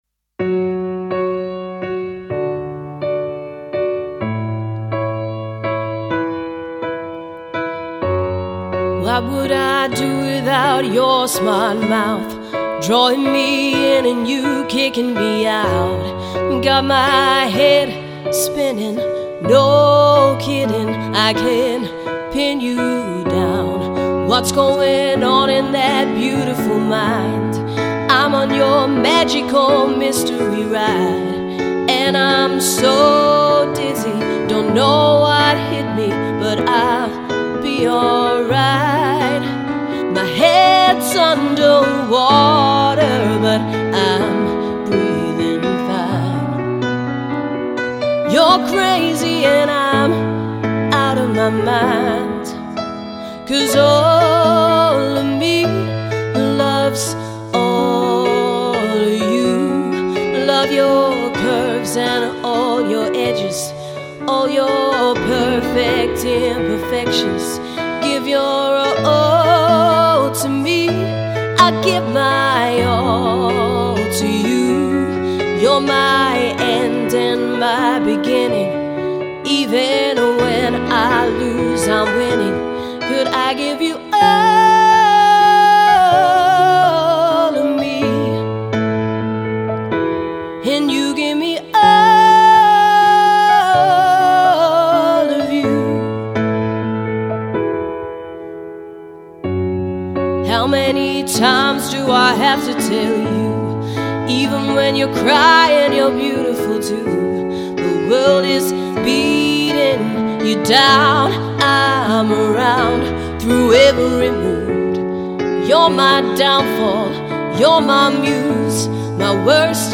female vocalist